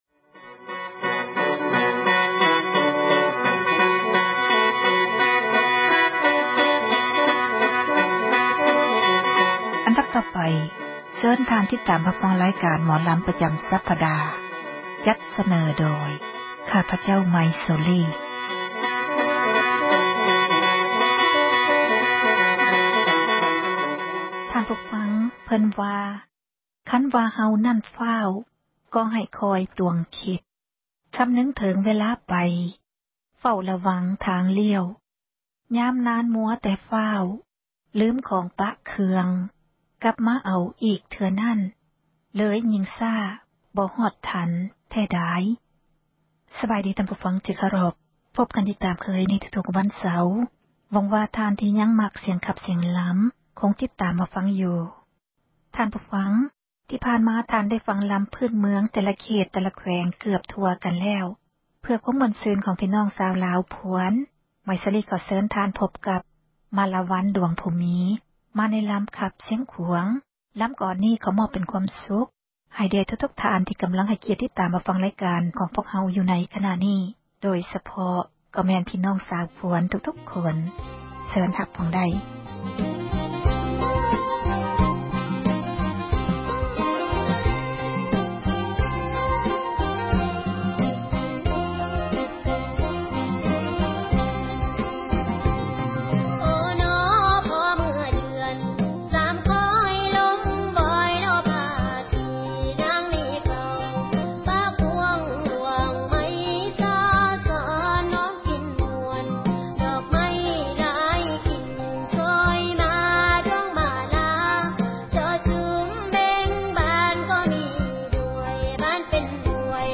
ຣາຍການ ໝໍລຳລາວ ປະຈຳ ສັປດາ ສເນີໂດຍ